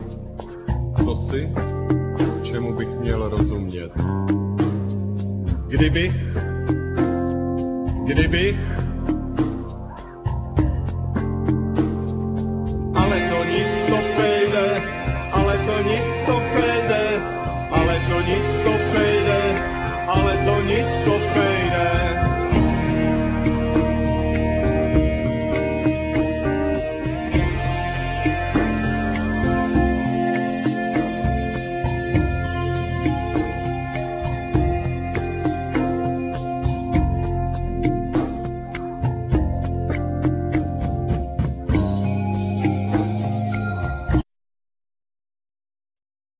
Vocals,Saxes,Guitars,Keyboards,Sirens
Drums,Percussions,Groove box,Electronics
Bass,Didgeridoo